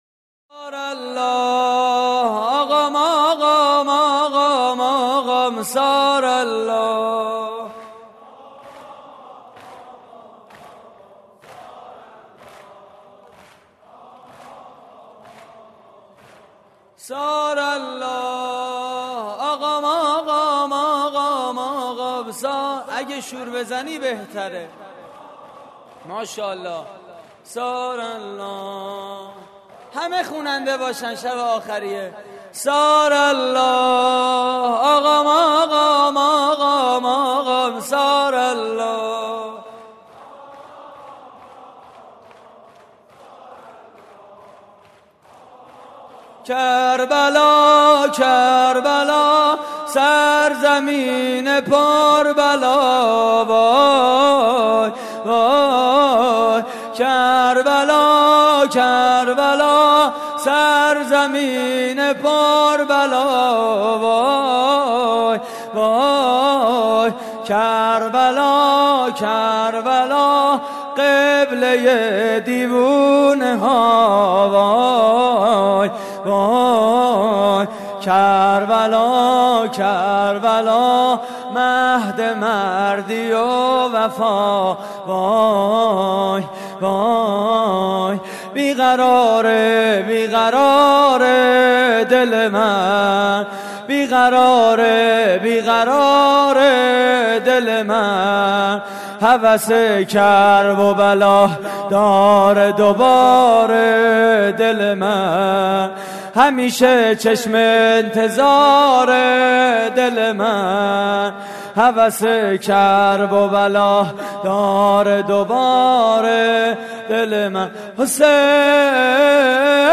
شور: کربلا سرزمین پربلا
مراسم عزاداری شهادت امام سجاد (ع)